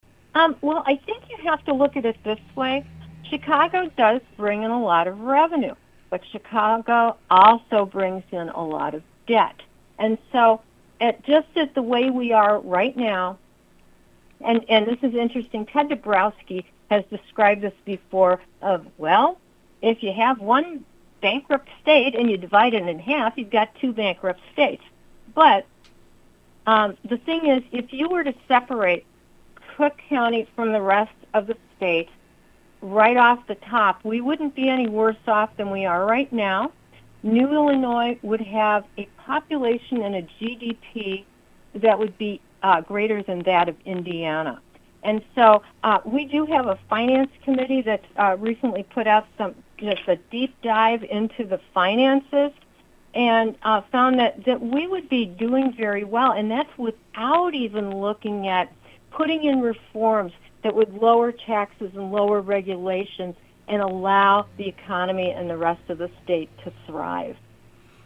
new-il-interview-part-4.mp3